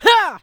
CK发力05.wav
CK发力05.wav 0:00.00 0:00.43 CK发力05.wav WAV · 37 KB · 單聲道 (1ch) 下载文件 本站所有音效均采用 CC0 授权 ，可免费用于商业与个人项目，无需署名。
人声采集素材/男2刺客型/CK发力05.wav